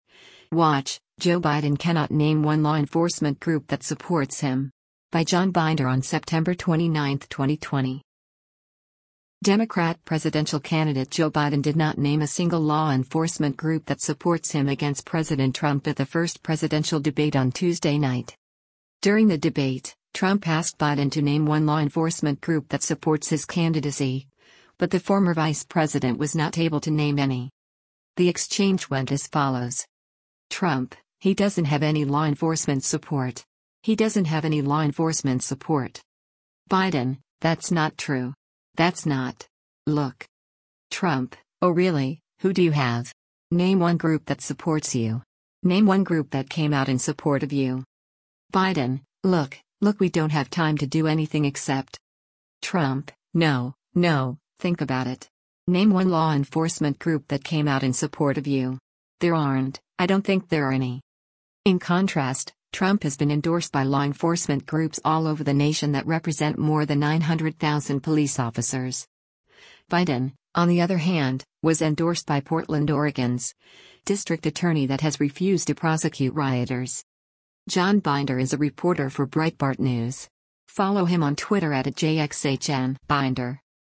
Democrat presidential candidate Joe Biden did not name a single law enforcement group that supports him against President Trump at the first presidential debate on Tuesday night.